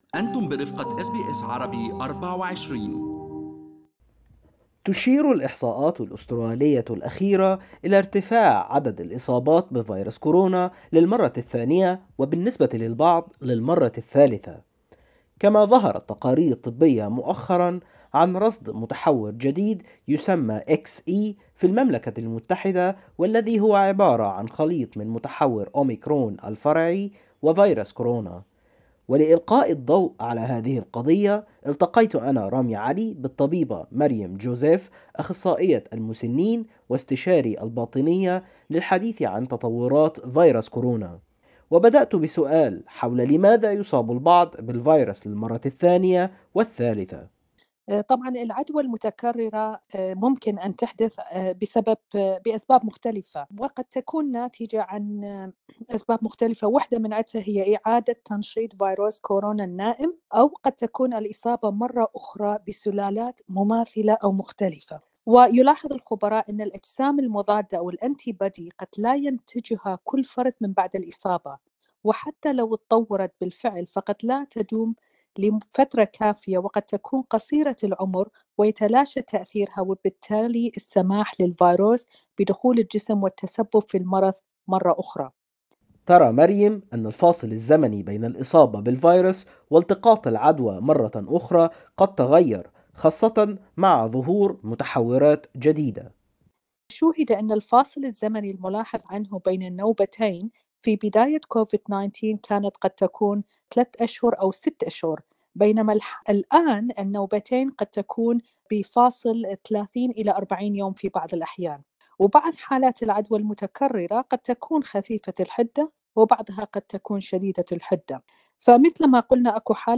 لماذا يصاب البعض منا بكورونا مرتين: طبيبة تشرح